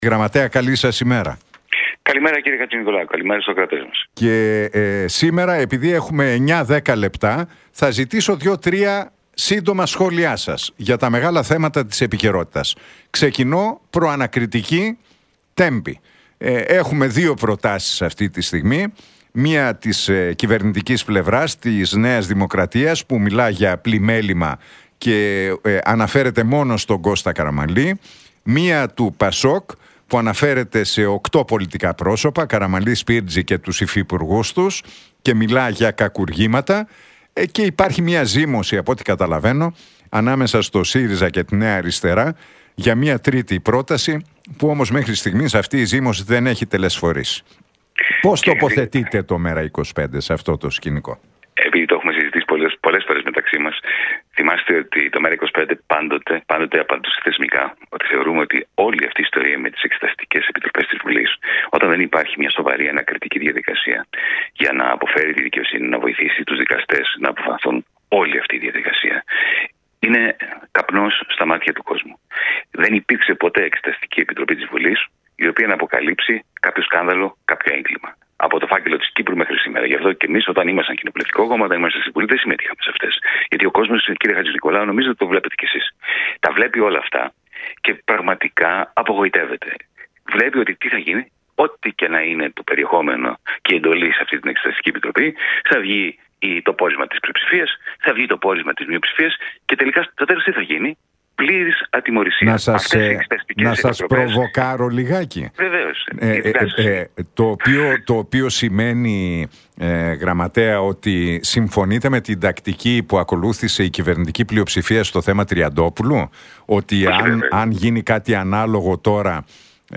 Για την Προανακριτική για τον Κώστα Καραμανλή, τον ΟΠΕΚΕΠΕ και το πρόγραμμα SAFE για την ευρωπαϊκή άμυνα μίλησε ο γραμματέας του ΜέΡΑ25, Γιάνης Βαρουφάκης στον Νίκο Χατζηνικολάου από την συχνότητα του Realfm 97,8.